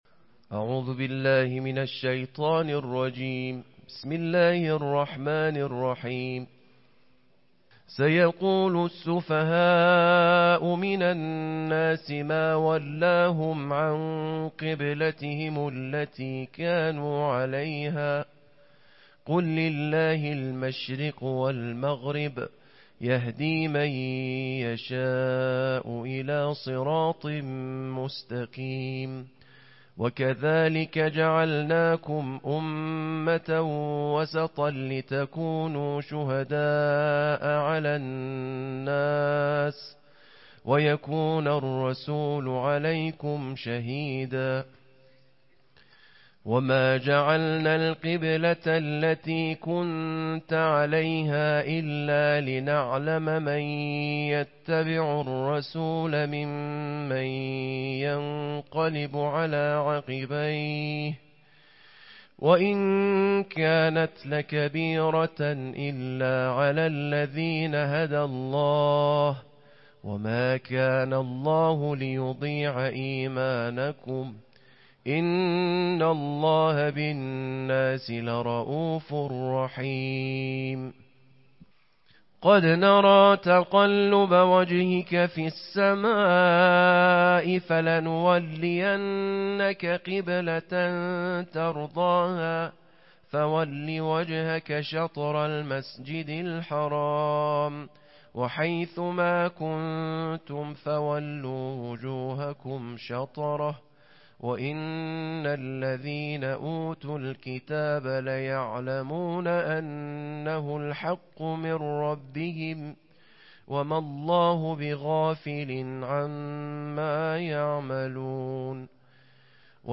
د قرآن کریم د دوهمې سپارې د ترتیل تلاؤت
د نړیوالو قاریانو په زړه پورې غږ سره د قرآن کریم د دوهمې سپارې د ترتیل تلاؤت